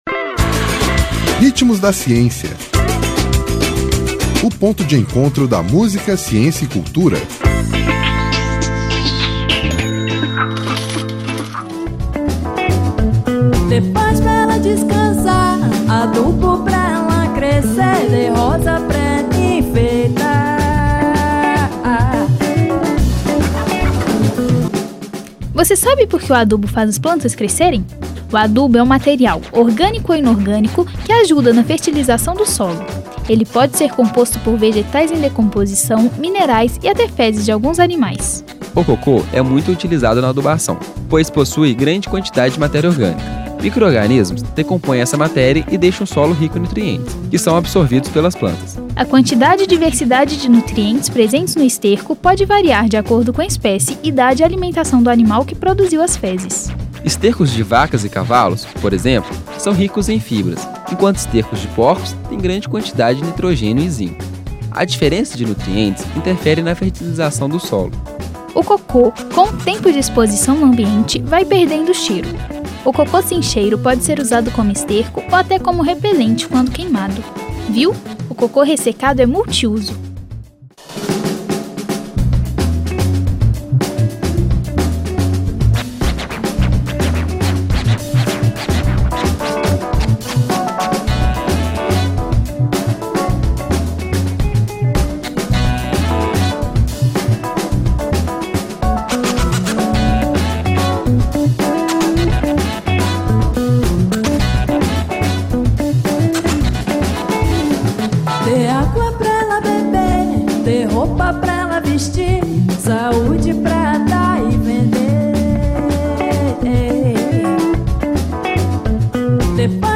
Intérprete: Céu